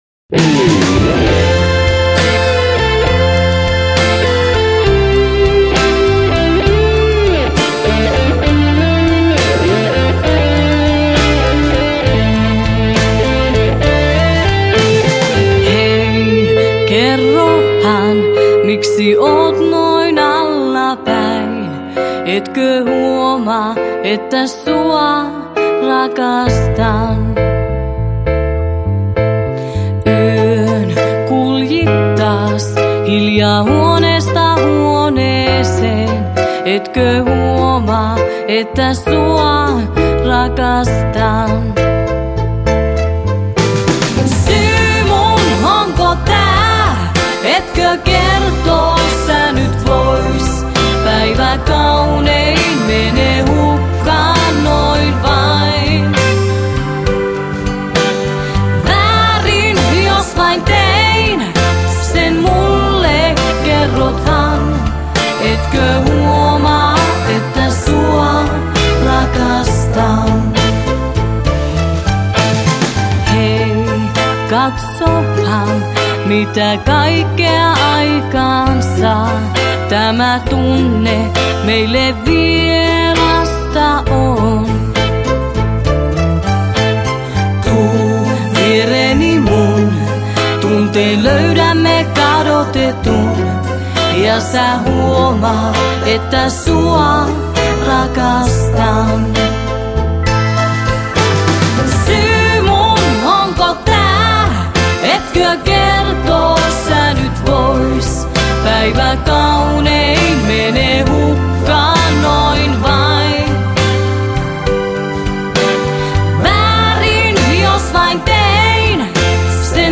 laulut
kaikki soittimet, rumpujen ohjelmointi, taustalaulut